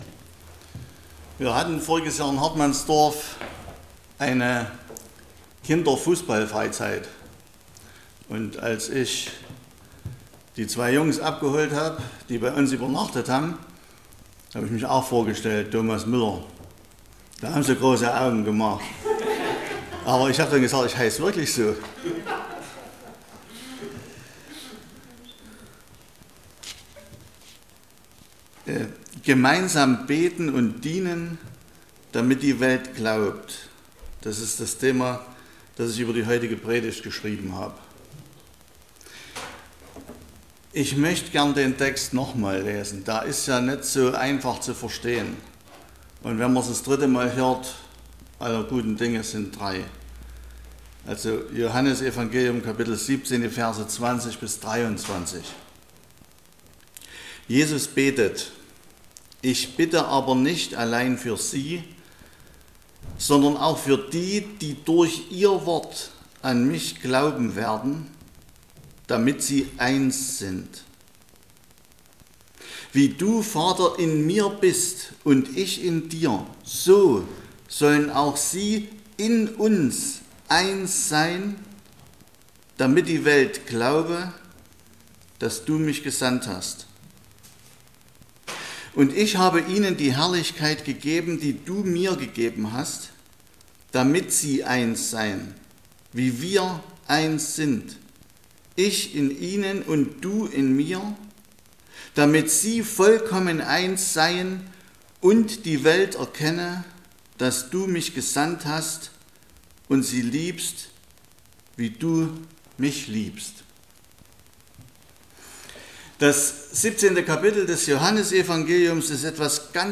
Eine Gastpredigt